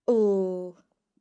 The broad L sound is made by resting your tongue behind your front teeth while pronouncing the letter, and occurs when the L is next to an a, o, or u. The broad L can be heard in latha (a day):